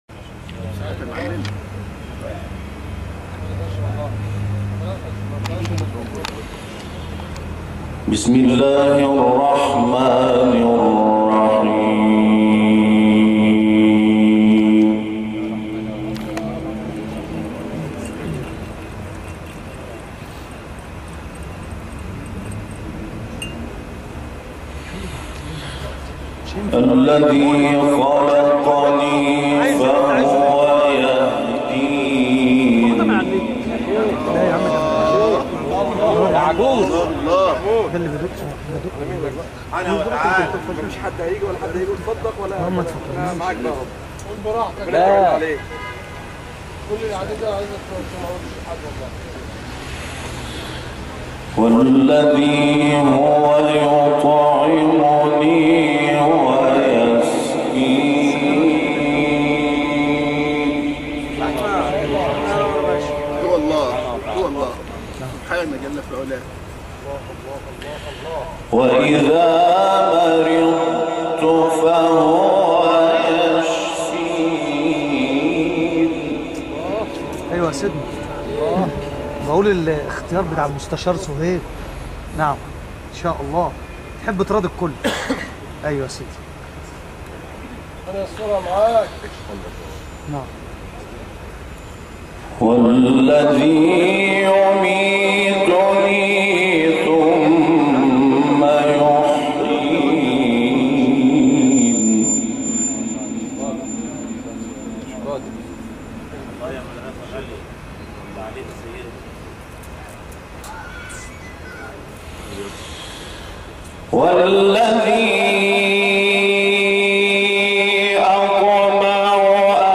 جدیدترین تلاوت «محمود شحات انور» در طنطا
گروه شبکه اجتماعی ــ تلاوت آیاتی از کلام‌الله مجید با صدای محمود شحات انور را می‌شنوید.